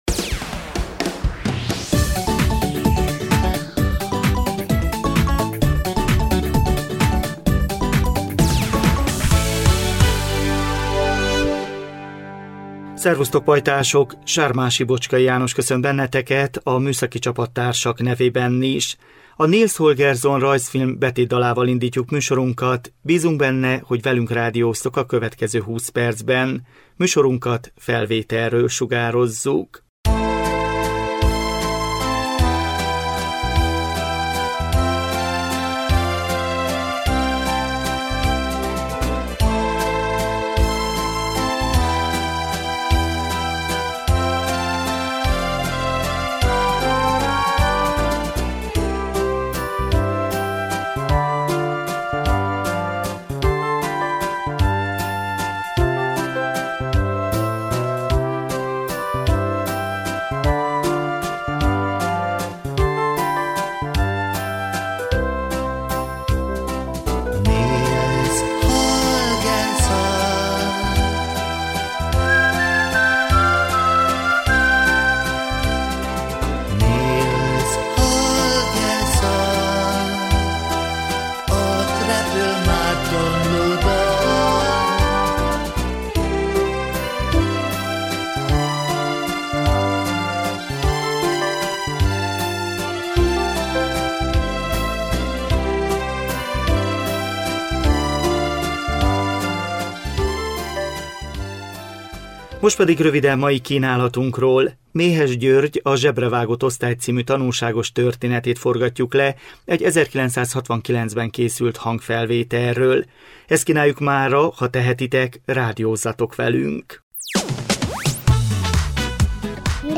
A 2020 március 31-én jelentkező GYERMEKMŰSOR tartalma: Méhes György – A zsebrevágott osztály című tanulságos történetét forgatjuk le egy 1969-ben készült hangfelvételről.